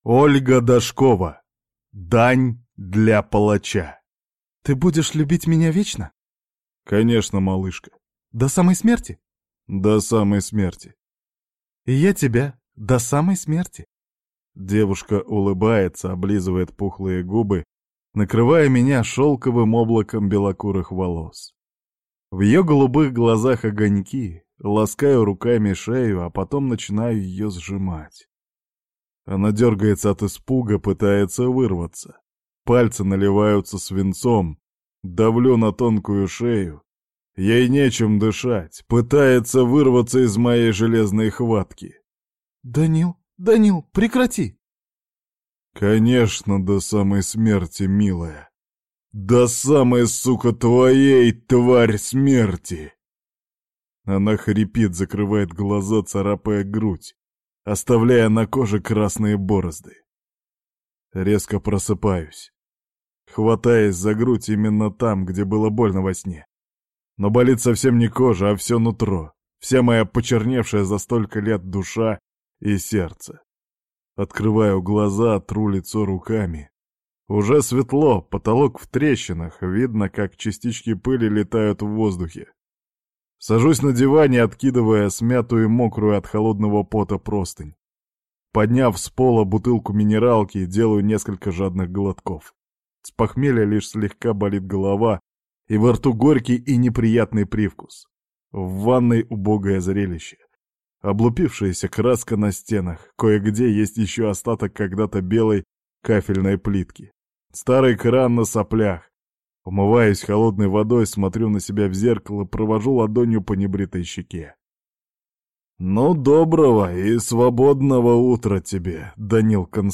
Аудиокнига Дань для палача | Библиотека аудиокниг